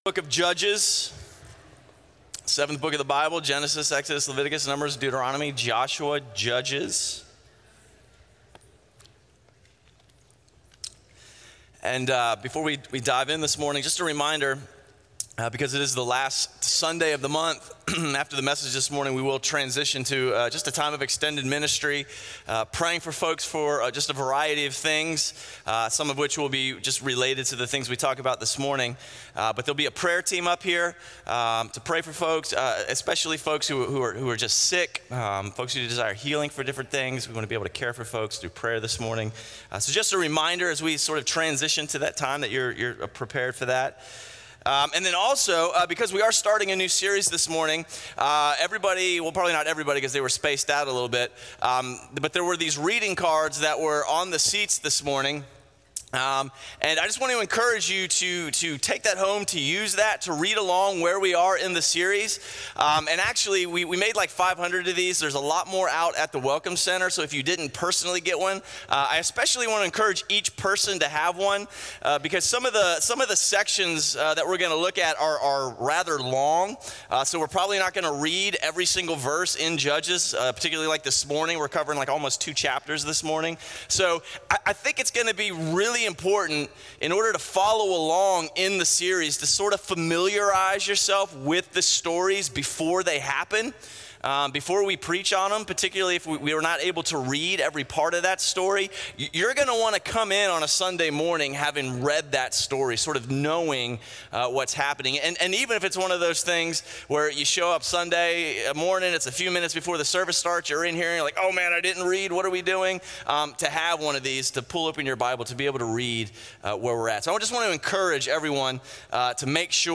A message from the series "Called Out."